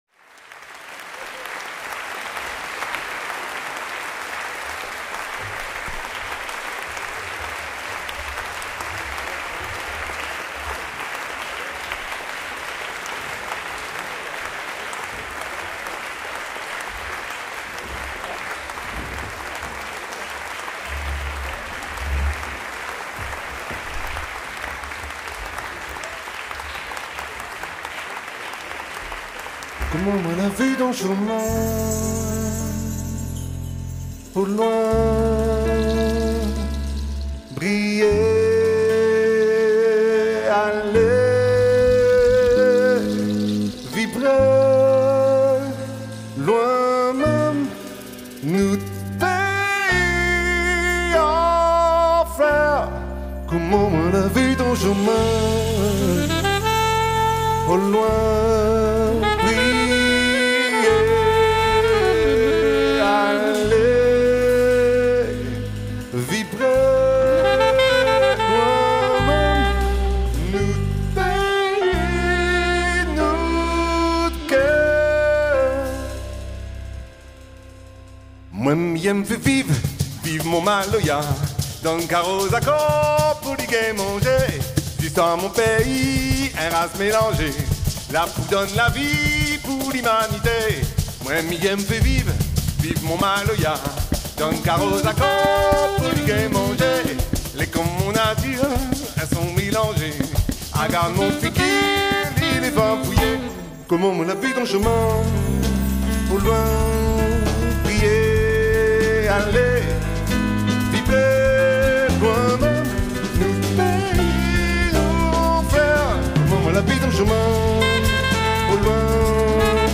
recorded May 12, 2024 - Phoenixhalle, Stuttgart
piano and vocals